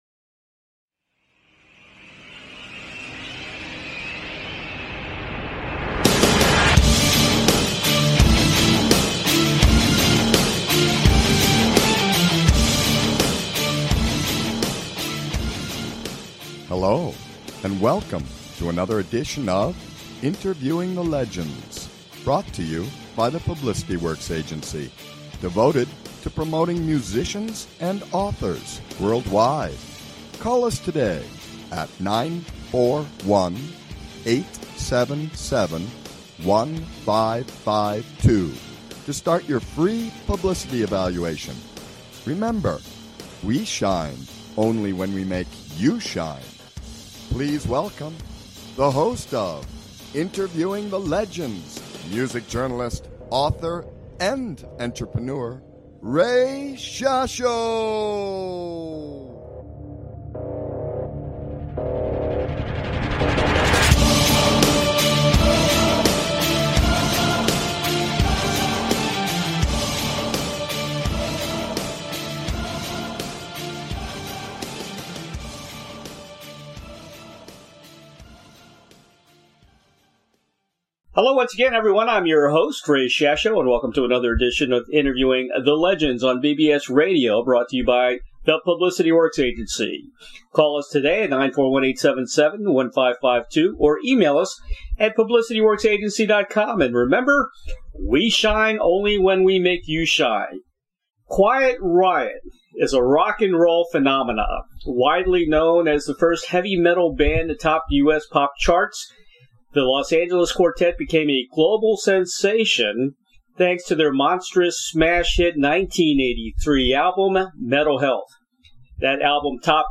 Guest, Frankie Banali